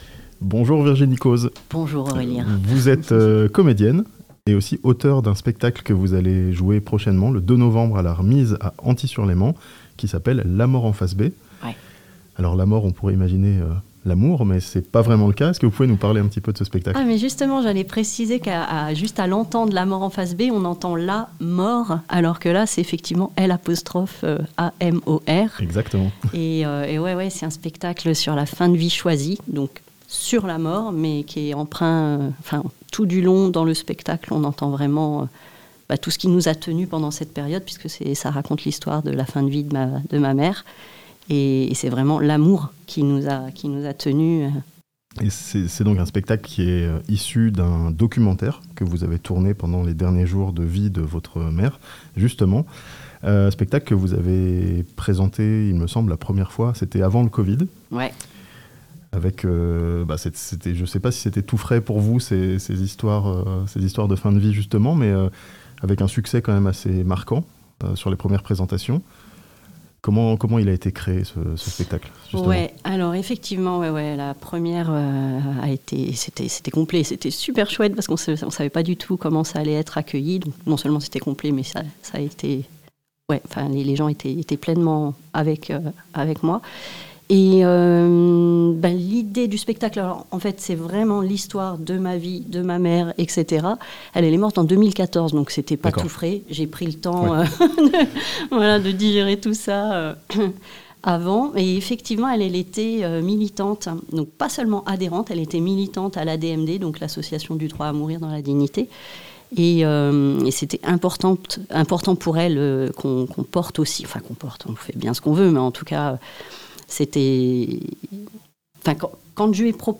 Un spectacle sur la fin de vie choisie, samedi 2 novembre à Anthy-sur-Léman (interview)